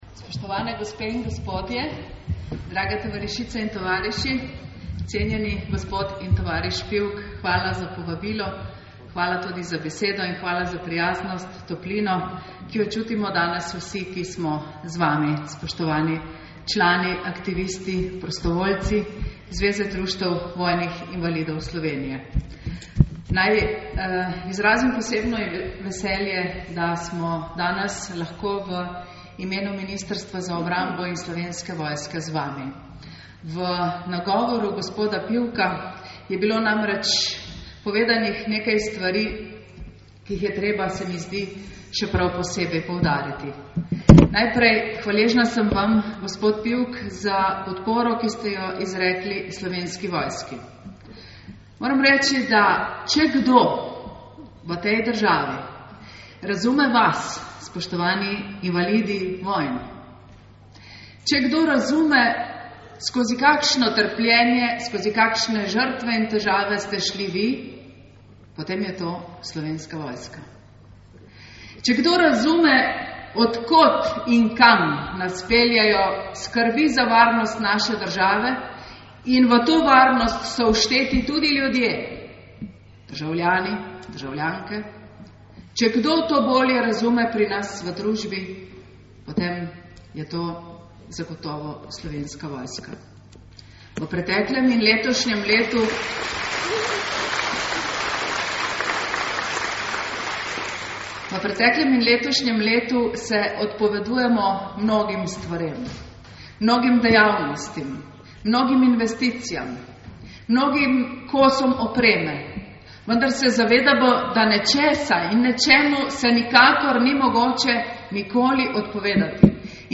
11. srečanja vojnih invalidov Slovenije, ki je potekalo v soboto 29. maja 2010 v dvorani Osnovne šole Koper, se je udeležilo približno 800 ljudi, članov društev vojnih invalidov, predstavnikov invalidskih, domoljubnih in veteranskih organizacij, predstavnik Slovenske vojske in drugi.
Ministrica za obrambo dr. Ljubica Jelušič je kot slavnostna govornica poudarila tesno vez in sodelovanje med Zvezo društev vojnih invalidov Slovenije ter drugimi domoljubnimi in veteranskimi organizacijami z Ministrstvom za obrambo ter s Slovensko vojsko, ki v dobrem in slabem zagotavljata trdno zavezništvo, razumevanje, spoštovanje ter medsebojno pomoč.
govor dr_ Jelušič na 11_ srečanju.mp3